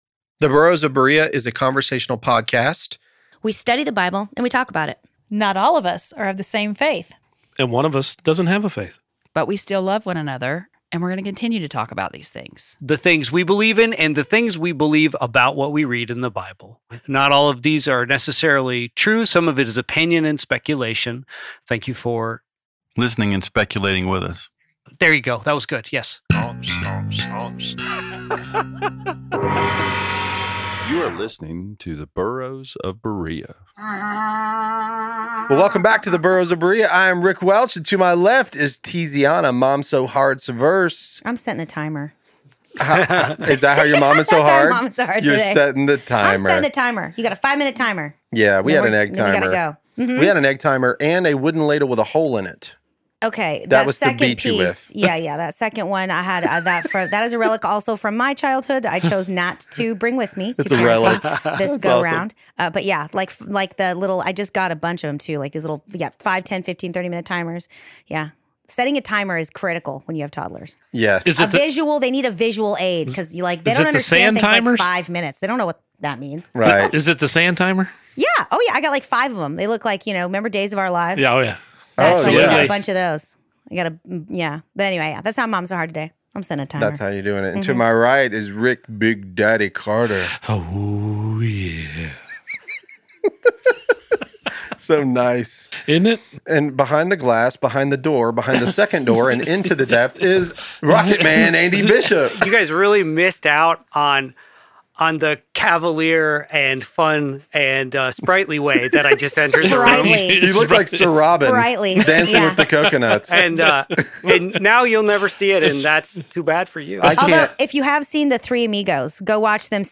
The Burros of Berea is a conversational podcast. We study the Bible and we talk about it.